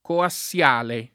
[ koa SSL# le ]